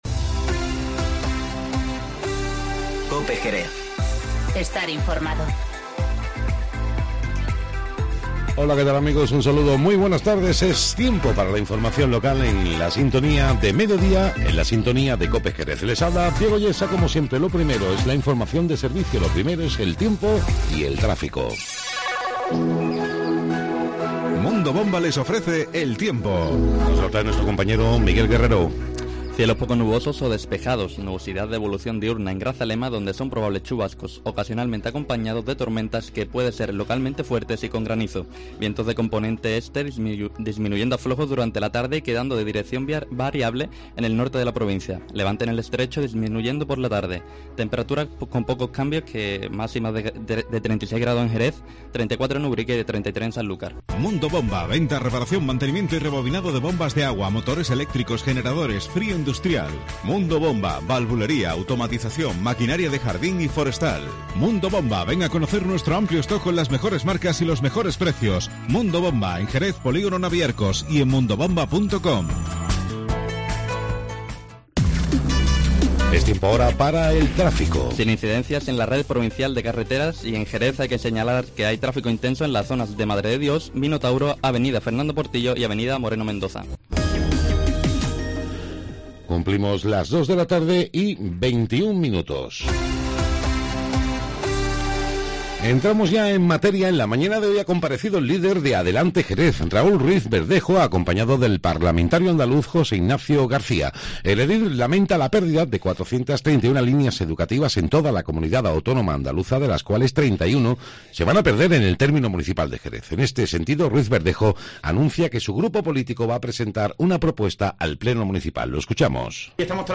Informativo Mediodía COPE Jerez- 04-09-19